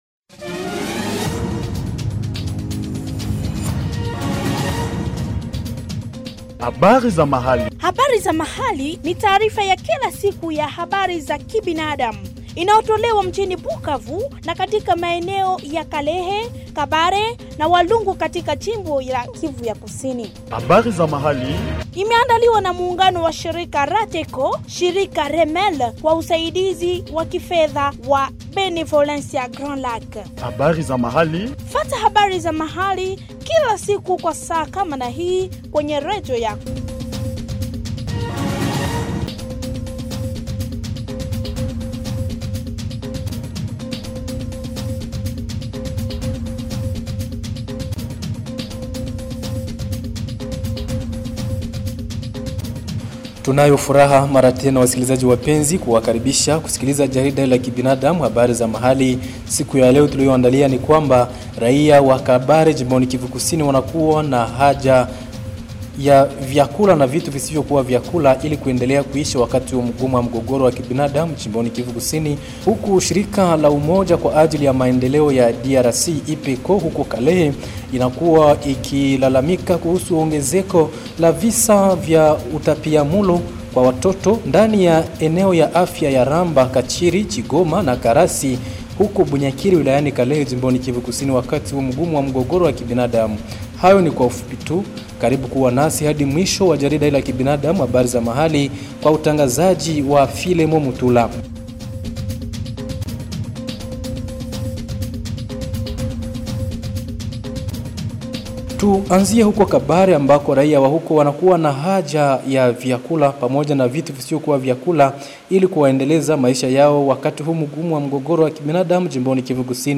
Suivez ici le bulletin Habari za Mahali du 19 décembre 2025 produit au Sud-Kivu